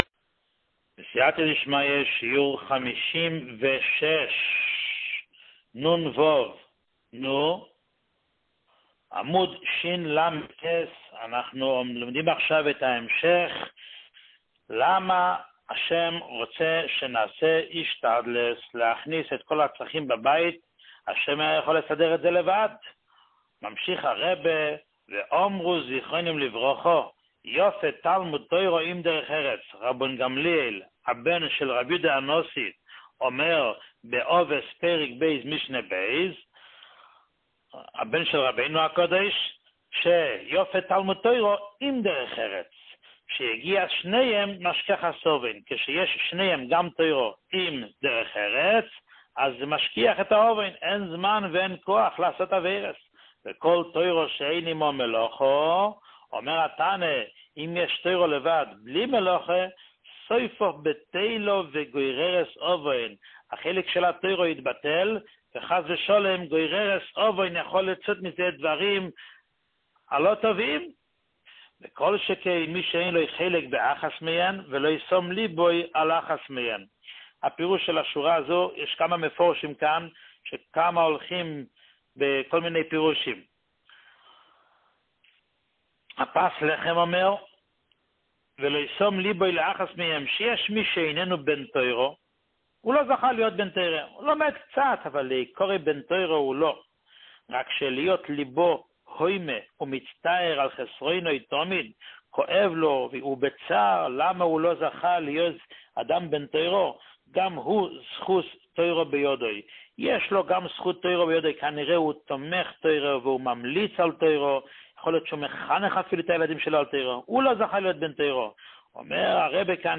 שיעור 56